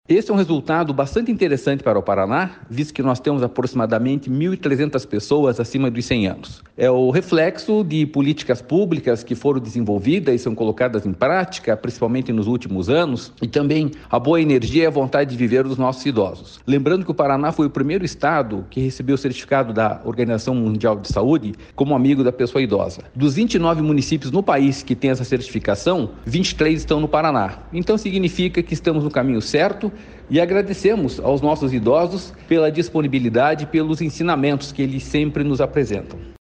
Sonora do diretor-presidente do Ipardes, Jorge Callado, sobre o aumento no número de idosos centenários no Paraná